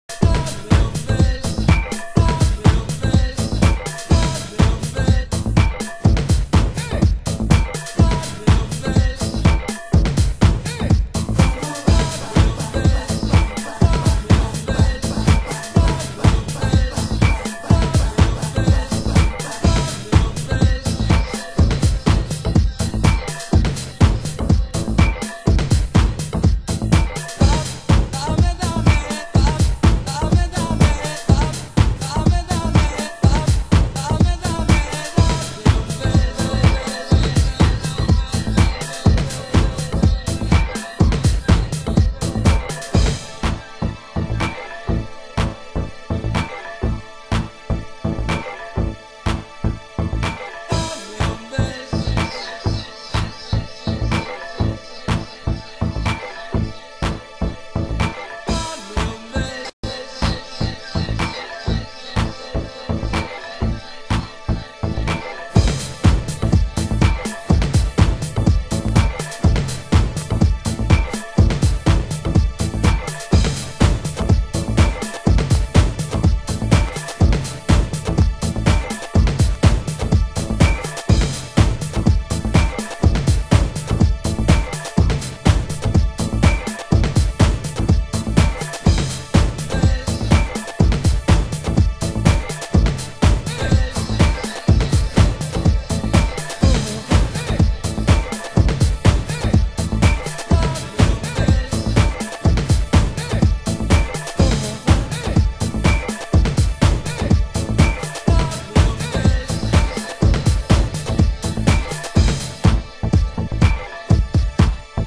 HOUSE MUSIC
盤質：少しチリノイズ有